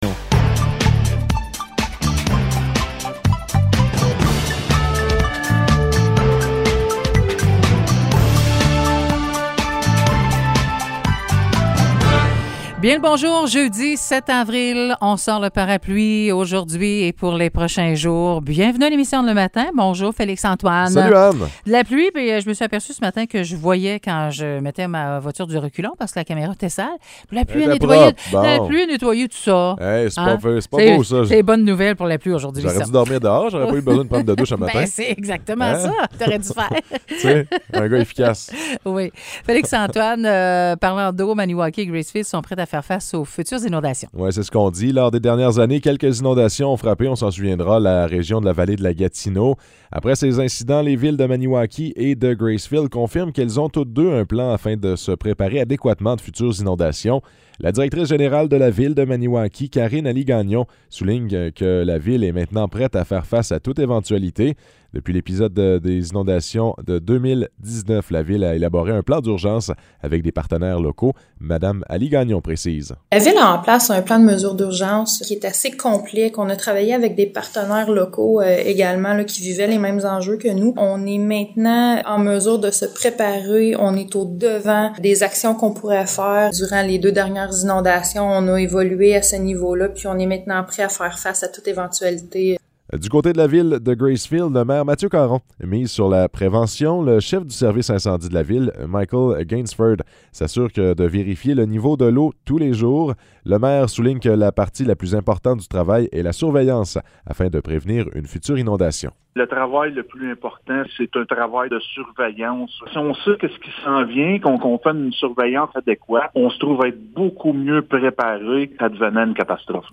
Nouvelles locales - 7 avril 2022 - 9 h